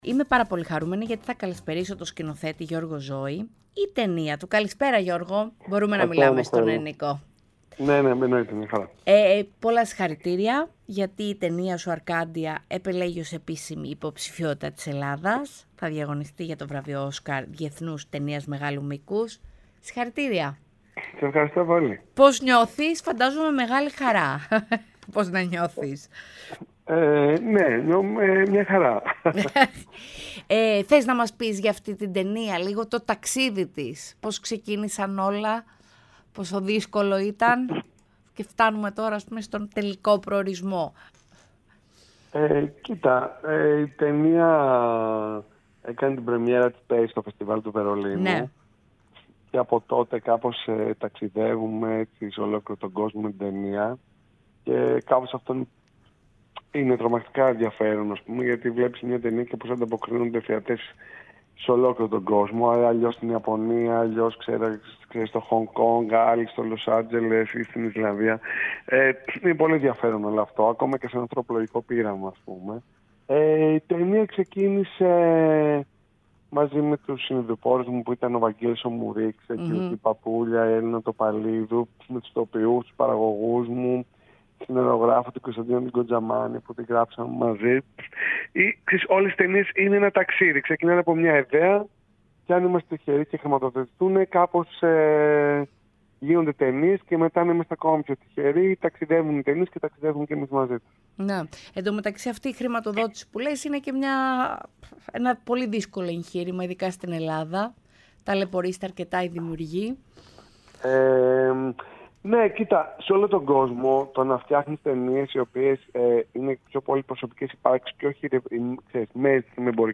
μίλησε στο ΕΡΤnews Radio 105,8